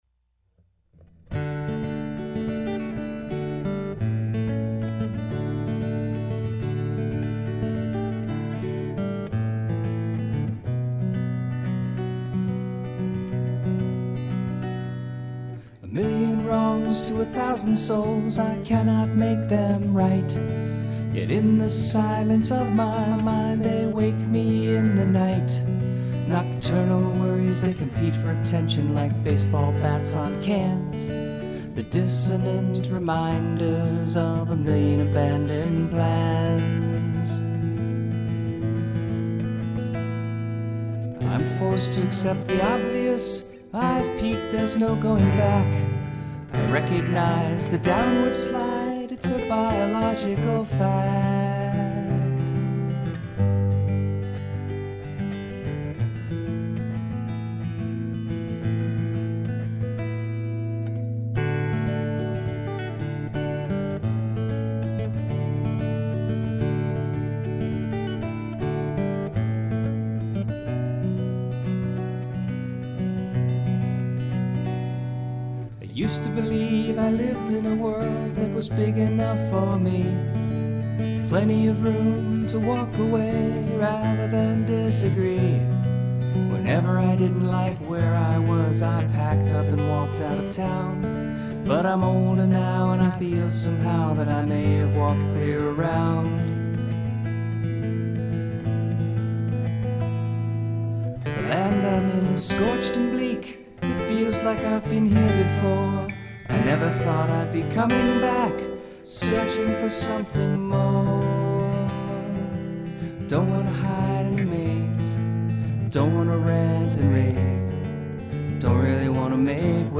Guitars, vocals